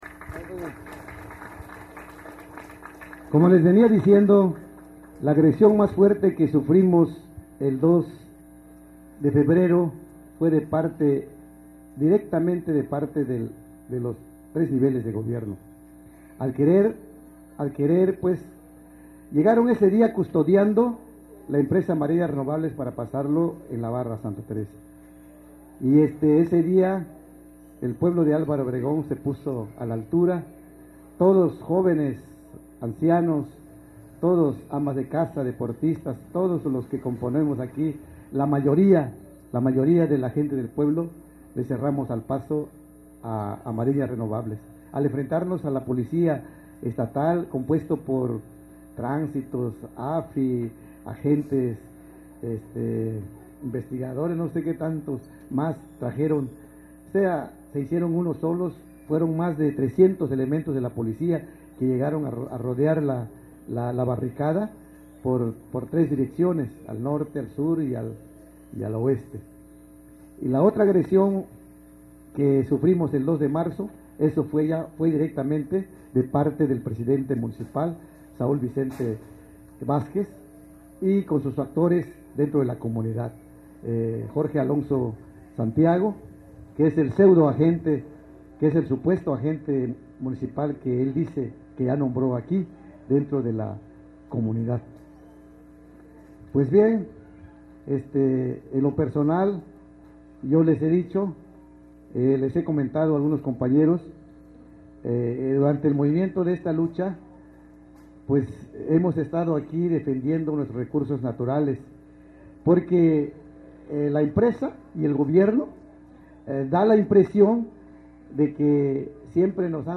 A continuación te invitamos a escuchar algunos audios de pobladores de esta comunidad y ver algunas imágenes de este territorio